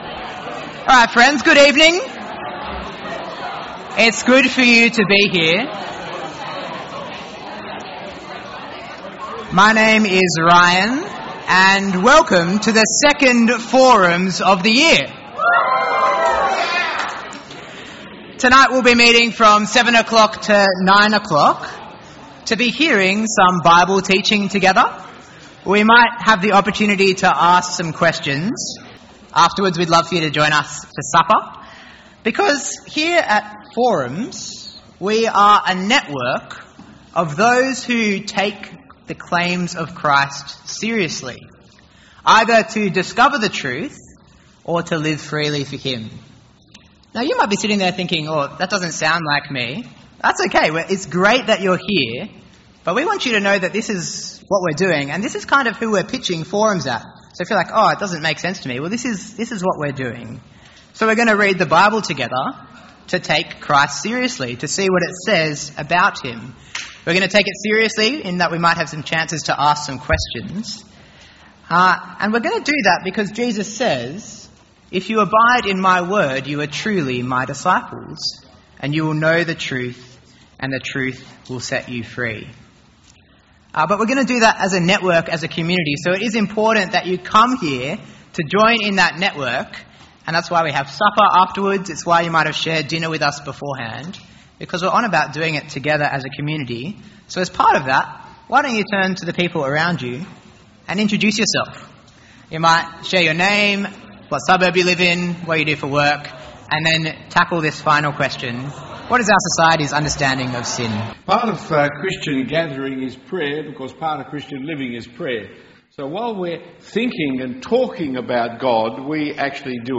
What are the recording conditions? Forum is a 2 hour session of interactive Bible teaching for 18-30 year olds.